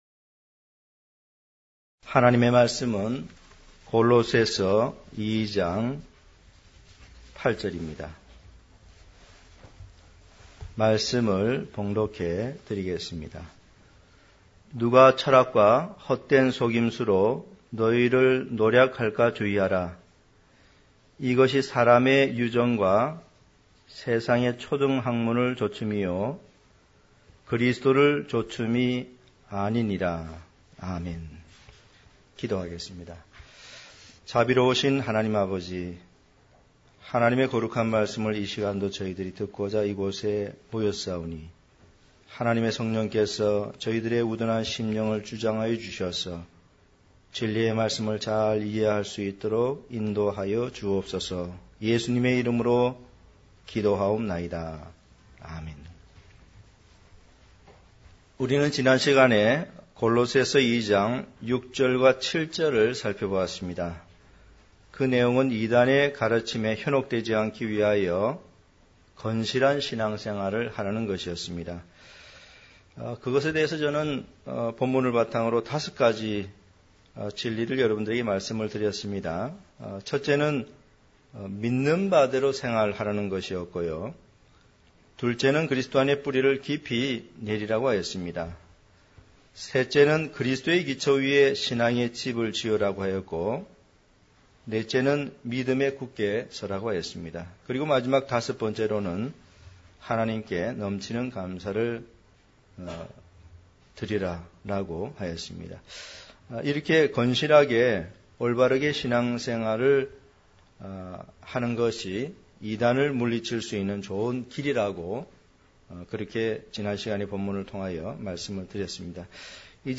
강해설교